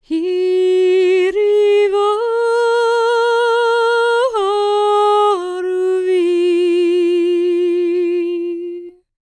L  MOURN A04.wav